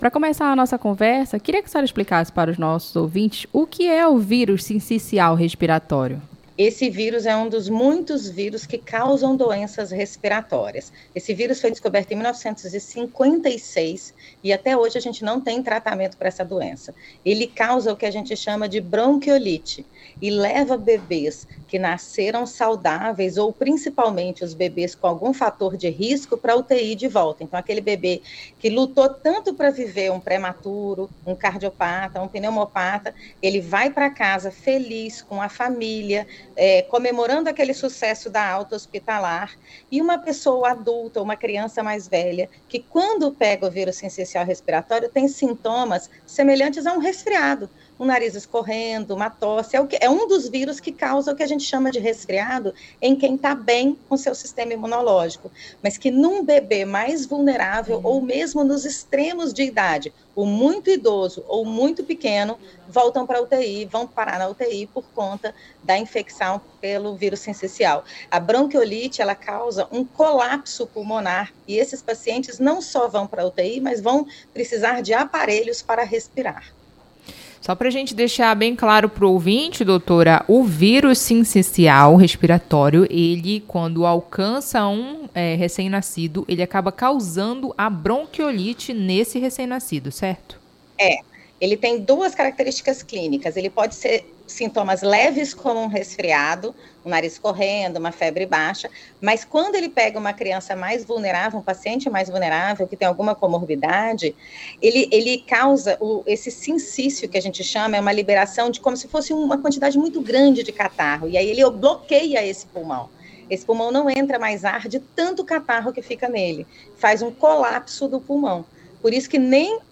ENTREVISTA-VRUS-SINCICIAL-.mp3